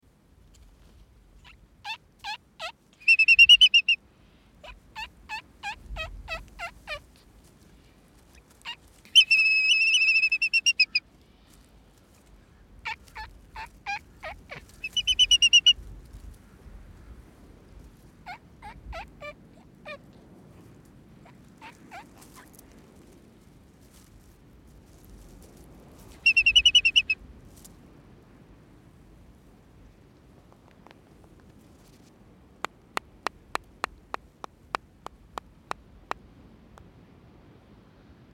コアホウドリ
【鳴き声】地鳴き 【聞きなし】「キュイーン」「キャッキャッ」